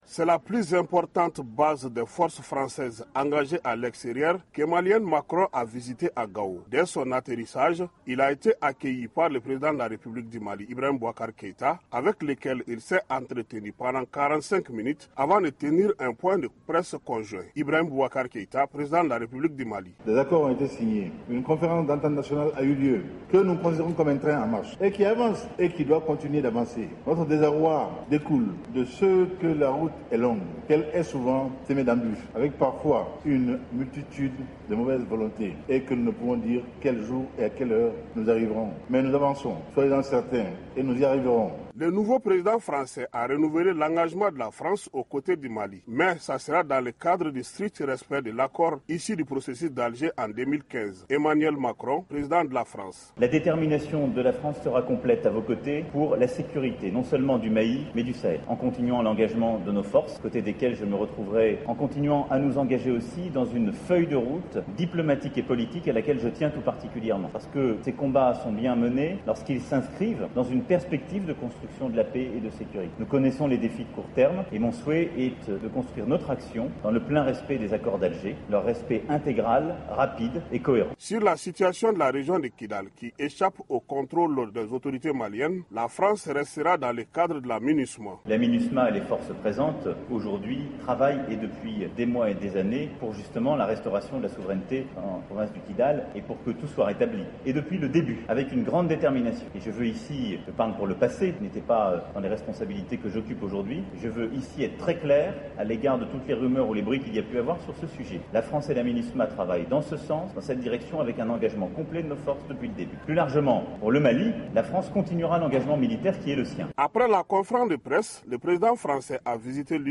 Reportage
à Gao